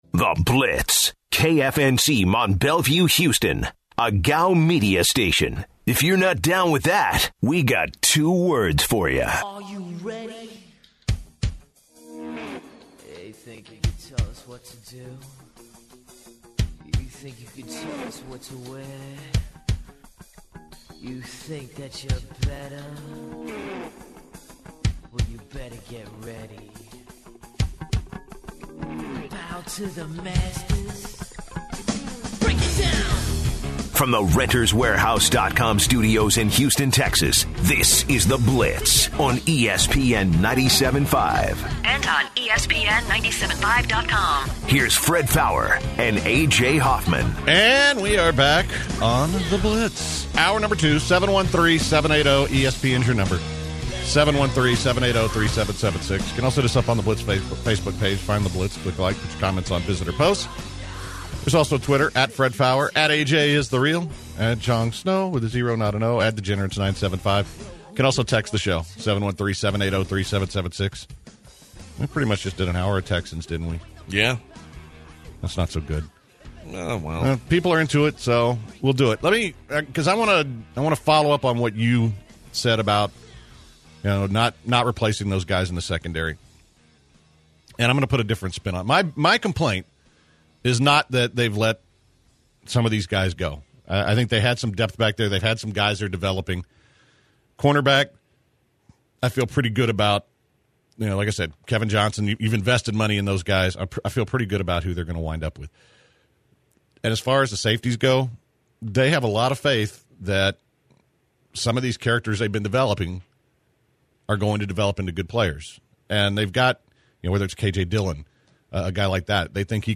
In hour two, the guys continue with the Texans and what they should do in free agency. Also, took listeners calls on Jay Cutler, Texans, and Rockets.